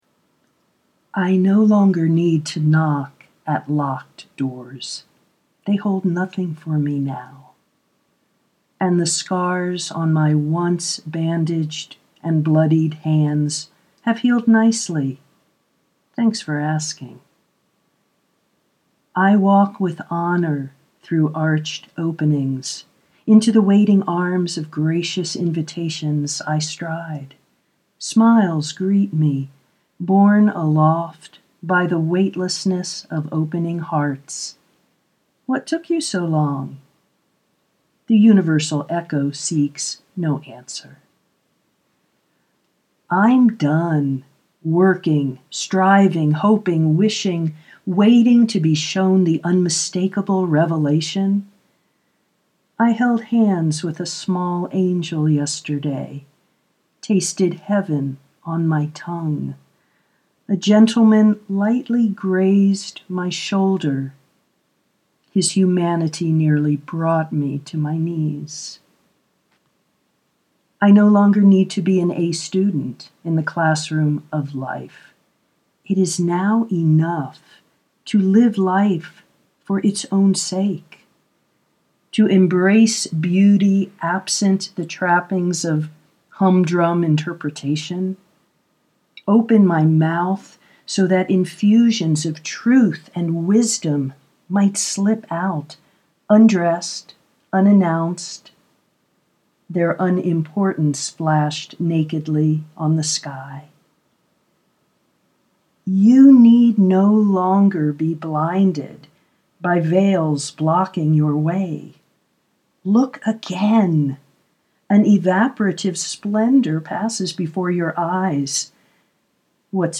knocking at locked doors holds nothing for you now (audio poetry 3:38)